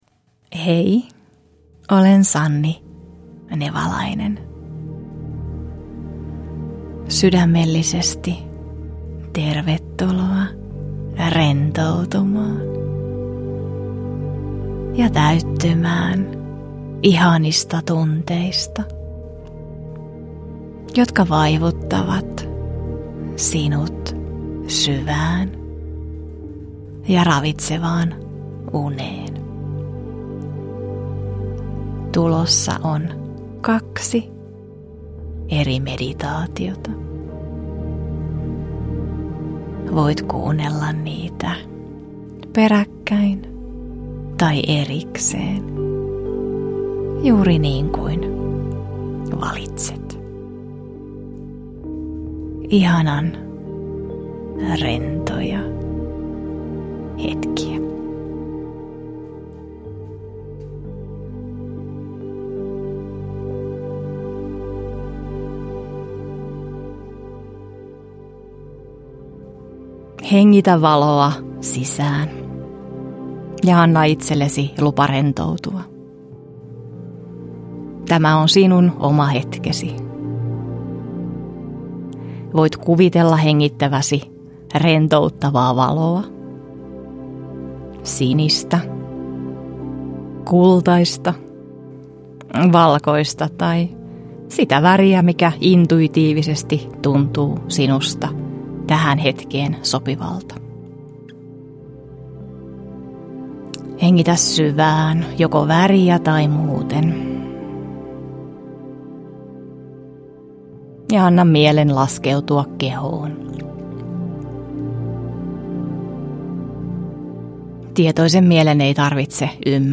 Rauhoittava ja rentouttava Iltameditaatiot -meditaatioäänite sisältää kaksi noin puolen tunnin meditaatiota: